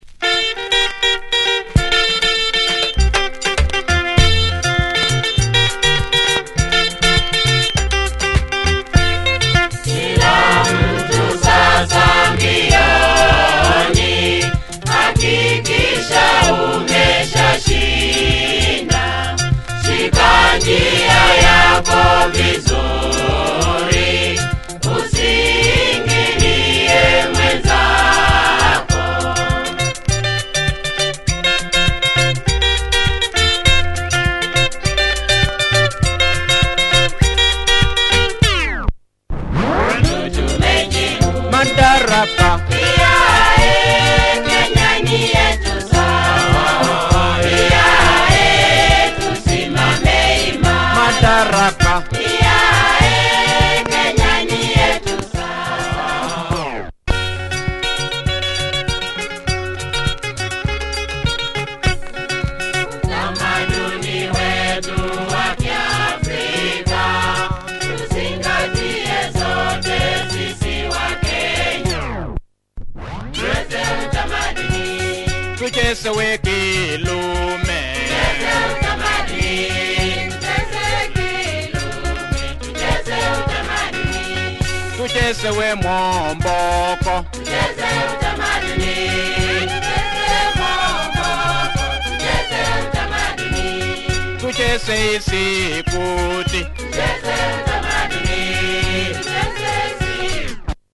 Stripped down trad groove with interesting percussion.